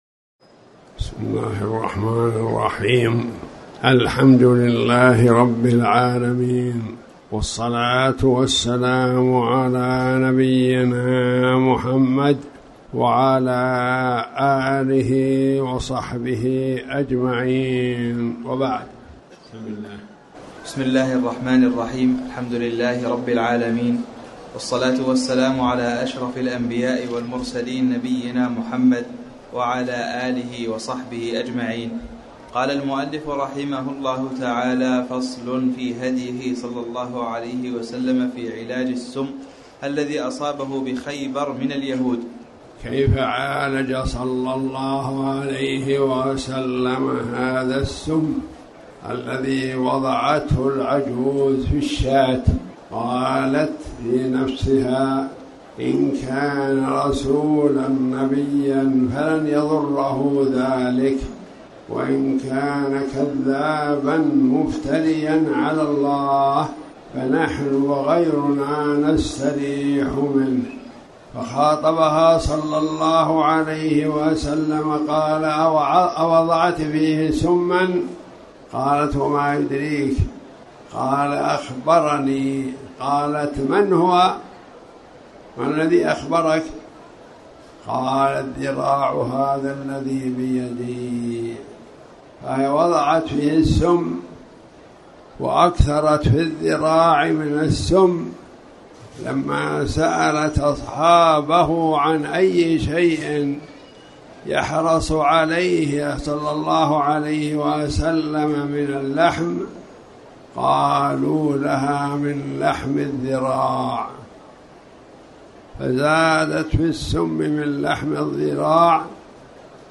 تاريخ النشر ٢٠ رجب ١٤٣٩ هـ المكان: المسجد الحرام الشيخ